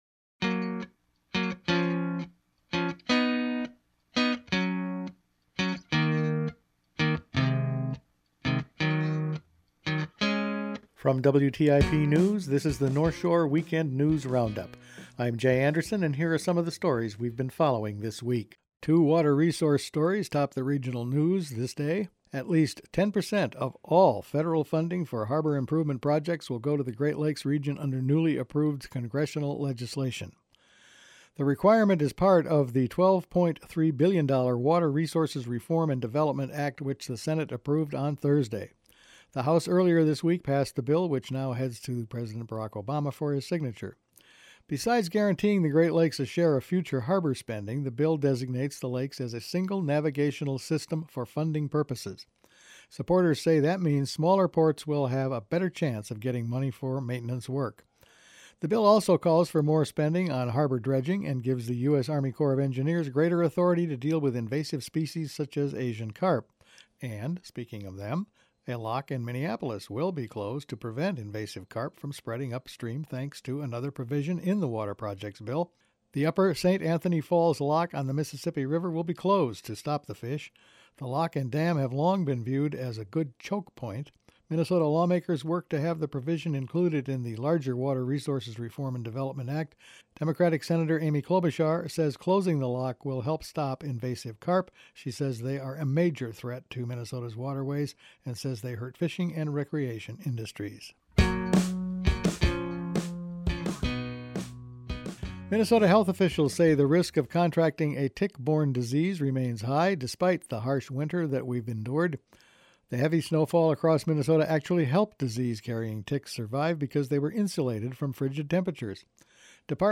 Each week the WTIP news team puts together a roundup of the week's news. County elections filing opened this week, a water resources bill promises more money for the Great Lakes, ticks survived the harsh winter…all this and more in this week’s news.